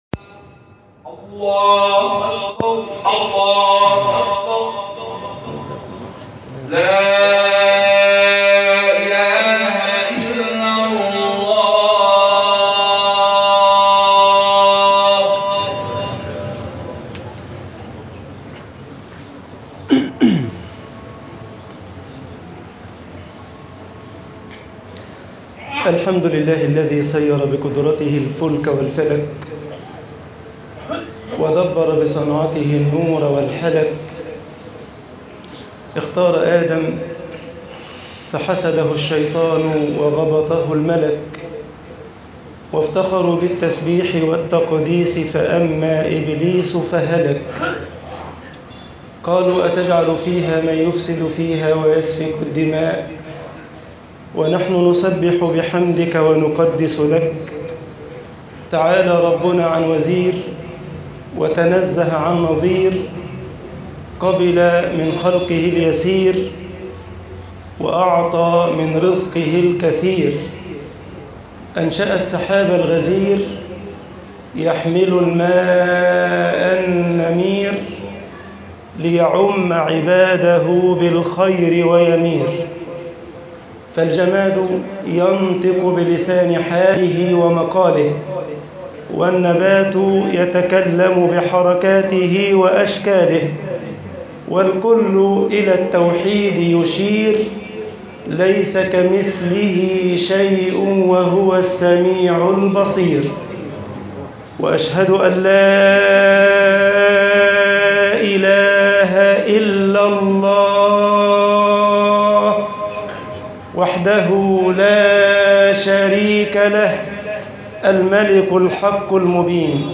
خطب الجمعة - مصر
khutab aljumaa misr 19_alibtilaa bain almanaa walataa.mp3